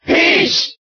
Category:Crowd cheers (SSBB) You cannot overwrite this file.
Peach_Cheer_German_SSBB.ogg.mp3